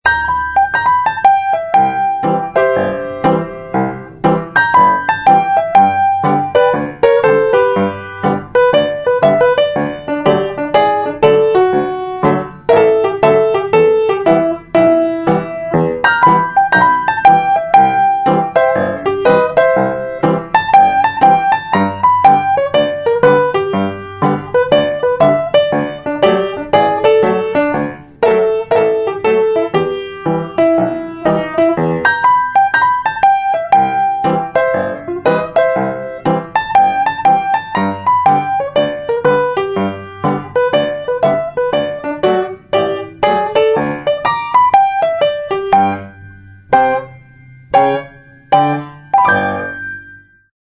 BUEN COVER